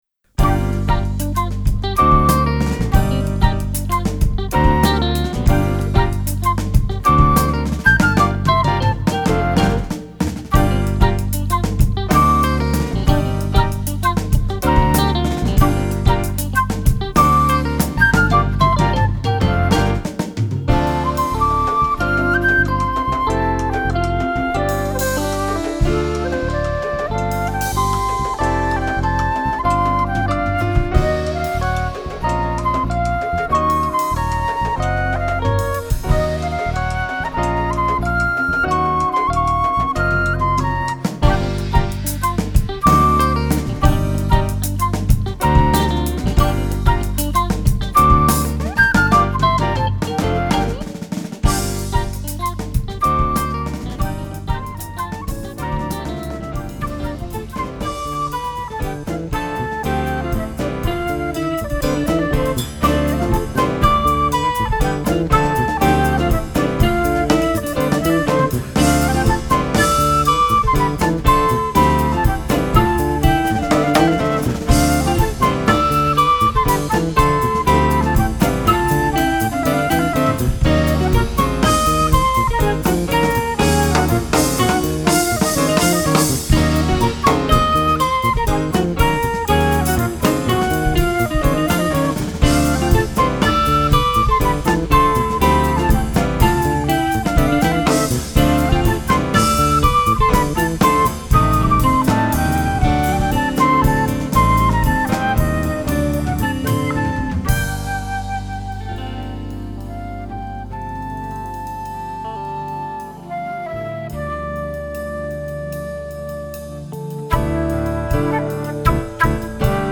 Argentine
Flûtes
Batterie et percussions
Guitare
Basse électrique